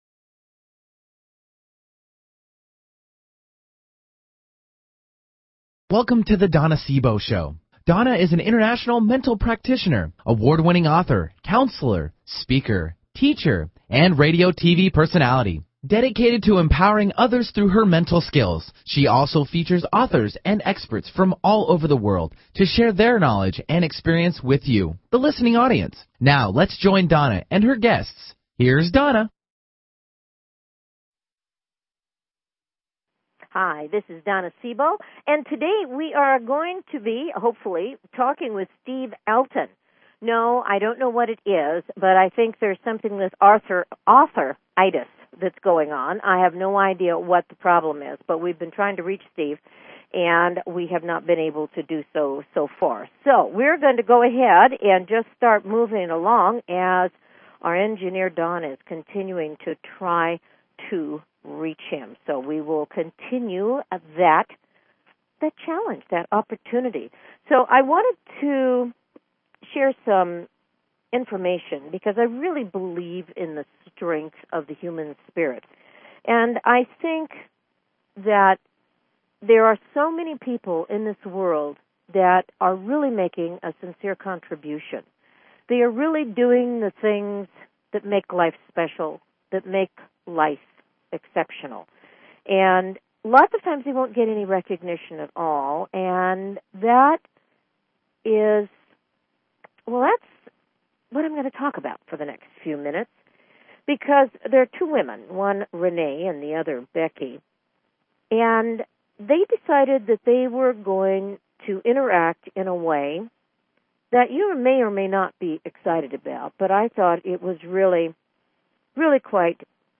Talk Show Episode, Audio Podcast
Callers are welcome to call in for a live on air psychic reading during the second half hour of each show.